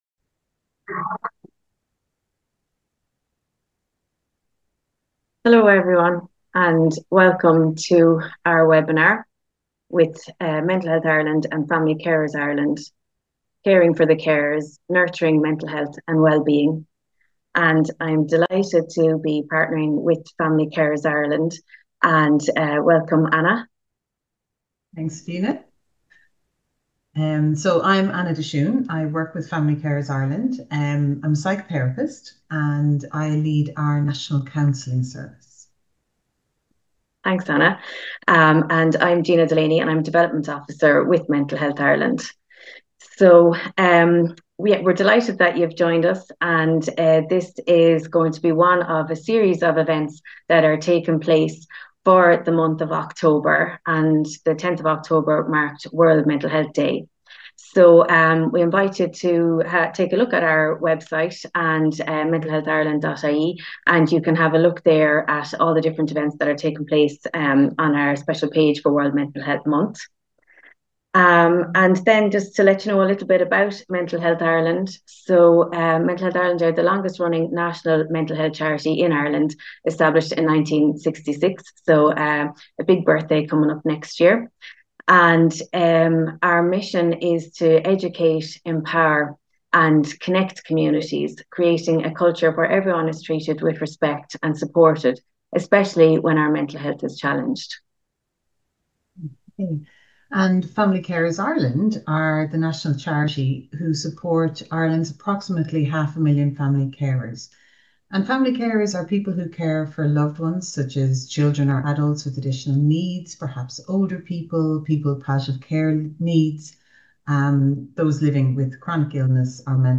Mental Health Ireland X Family Carers Ireland as part of the free partners webinar series during Mental Health Month 2025.